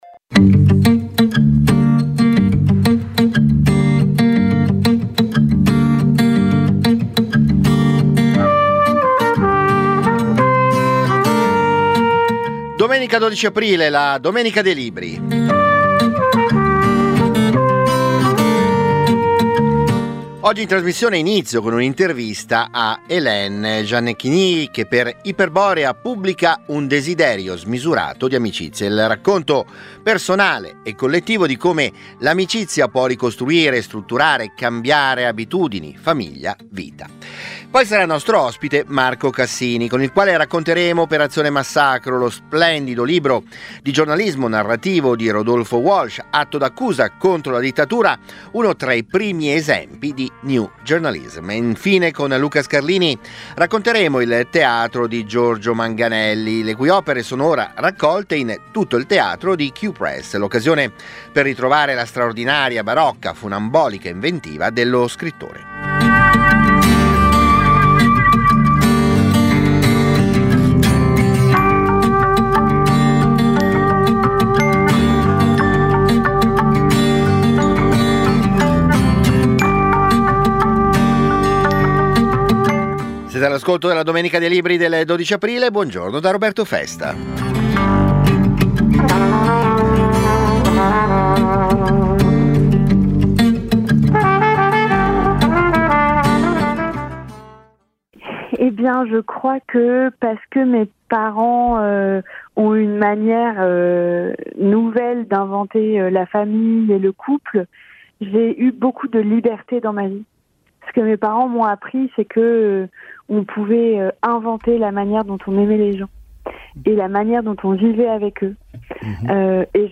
La domenica dei libri è la trasmissione di libri e cultura di Radio Popolare. Ogni domenica, dalle 10.35 alle 11.30, interviste agli autori, approfondimenti, le novità del dibattito culturale, soprattutto la passione della lettura e delle idee.